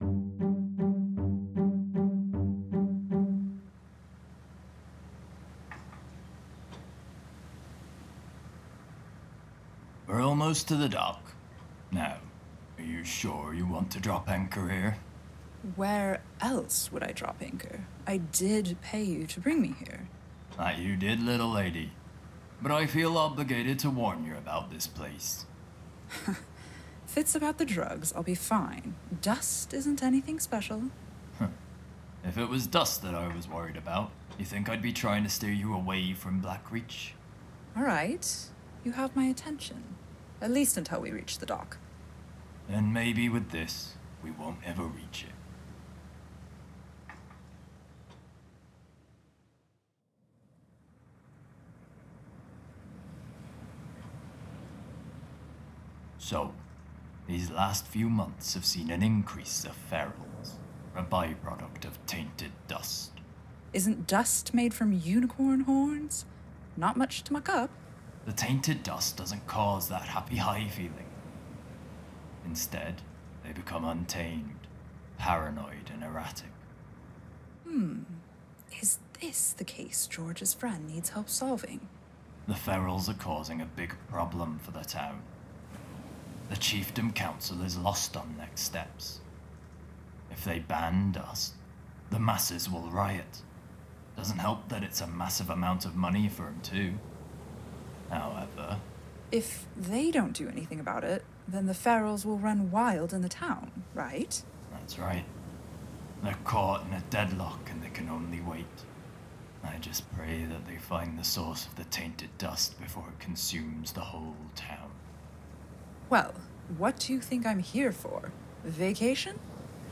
Luminaris Brimstone, Witch Investigator is a comedic fantasy adventure show starring our broke titular private investigator wandering around the world, alone, mixing business and pleasure while solving crimes and causing mayhem for any local she comes across.
… continue reading 15 episodios # Comedy # Audio Drama # Thrown Together Studios